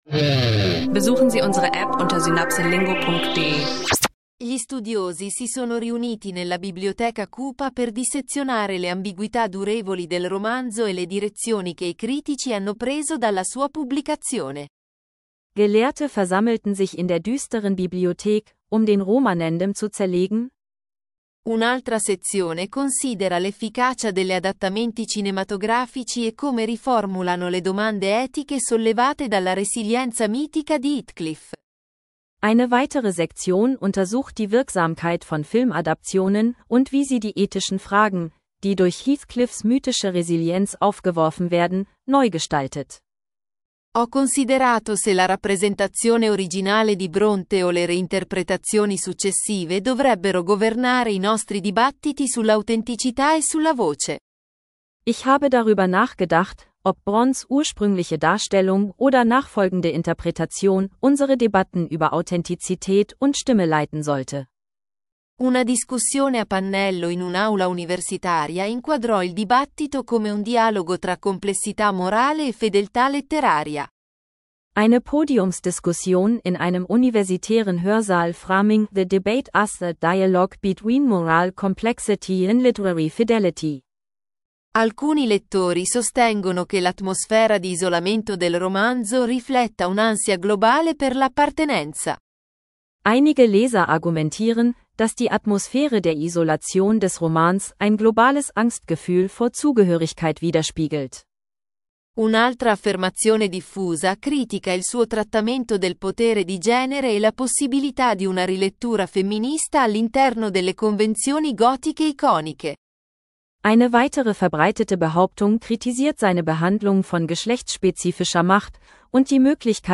SynapseLingo: Italienisch lernen Podcast – Debatte zu Wuthering Heights im Dialog